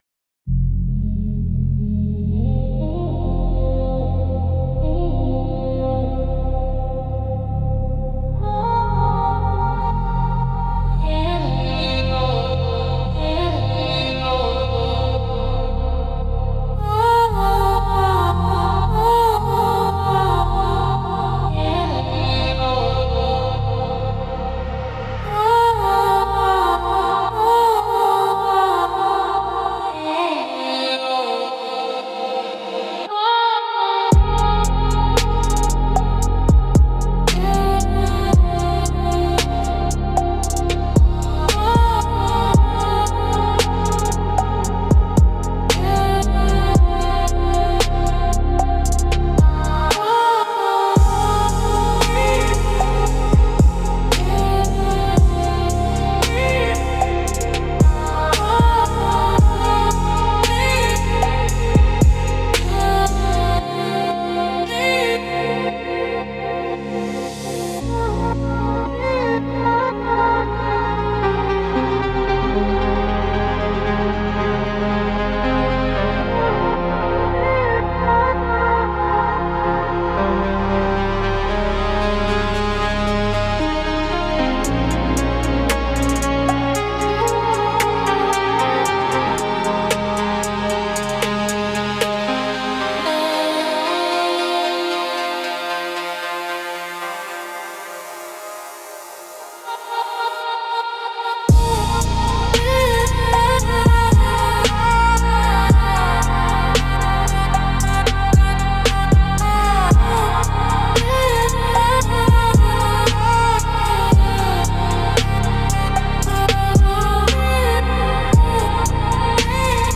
Instrumental - Low-End Lullaby 3.42